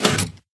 piston_out.ogg